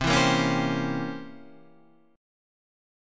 C 7th Flat 9th